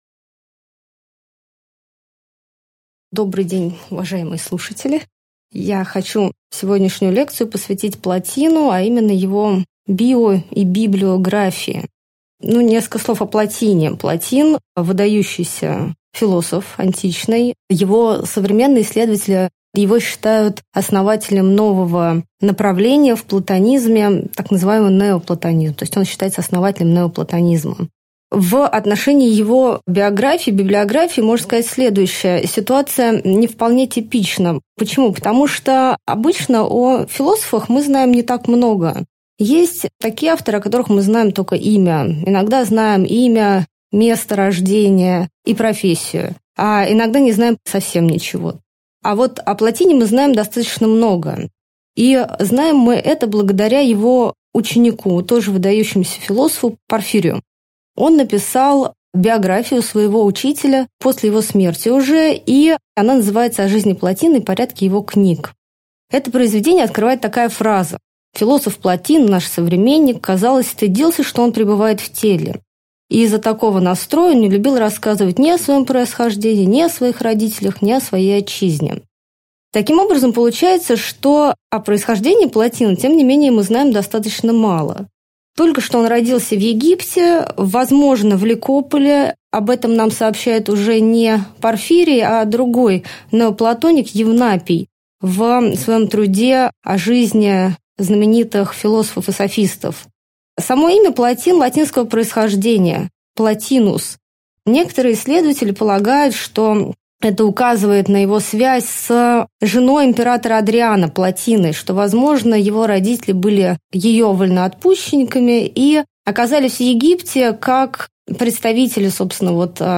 Аудиокнига Лекция «Жизнь и труды Плотина» | Библиотека аудиокниг